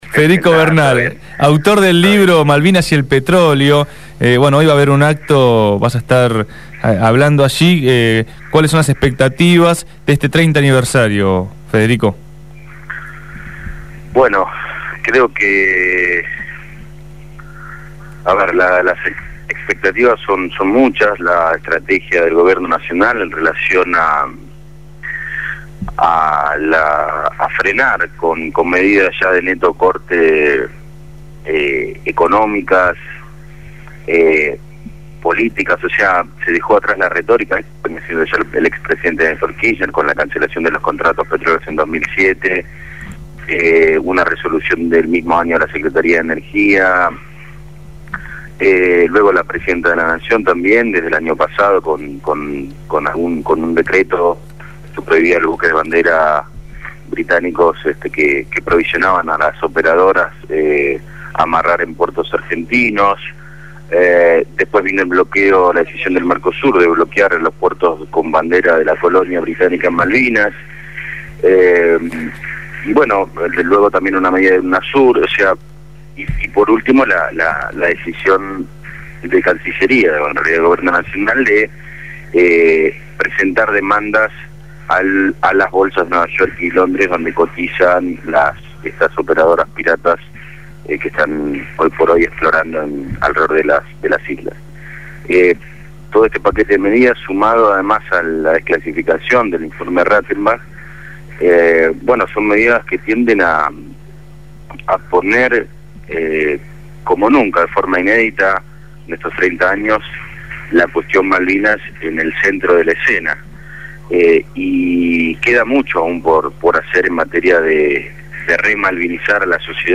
En diálogo